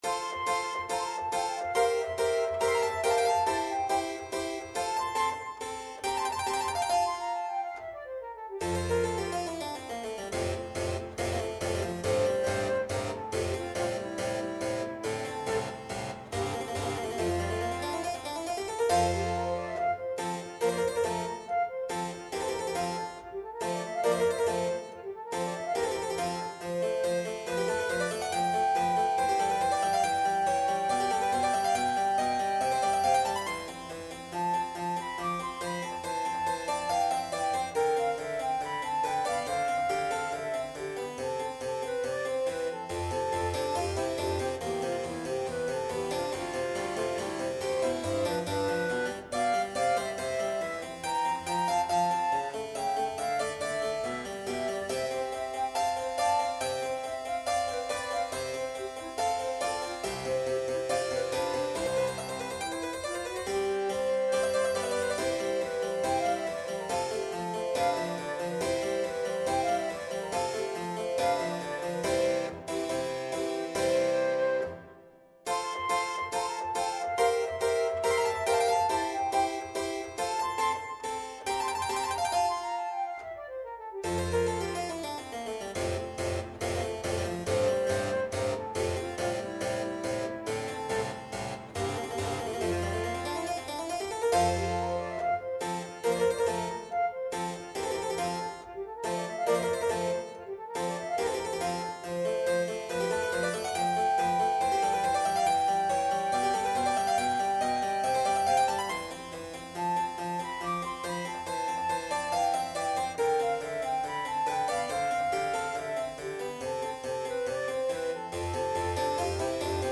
Instrument: Violin
Style: Classical